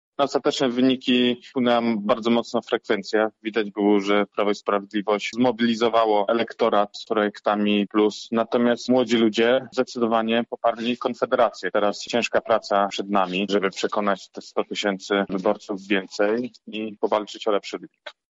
O tym, co mogło wpłynąć na wynik mówi Jakub Kulesza, poseł Konfederacji: